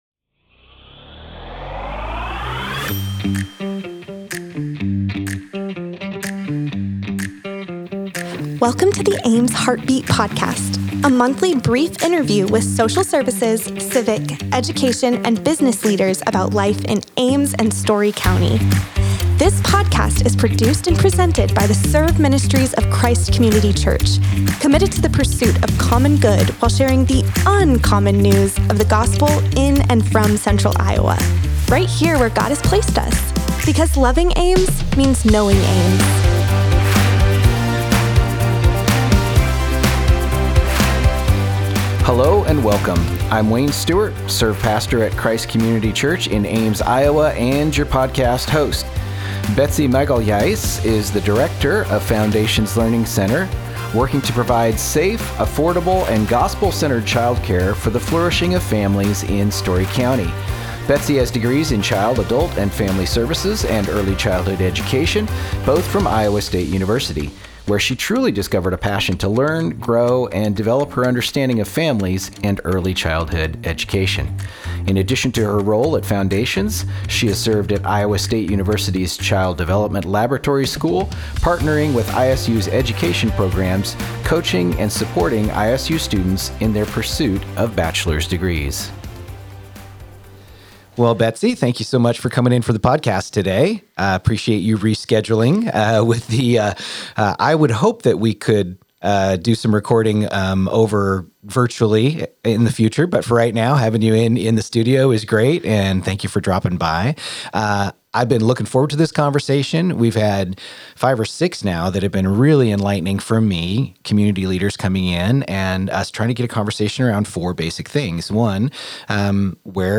The Ames Heartbeat Podcast features brief interviews with civic, education, social services, and business leaders in Ames and surrounding communities.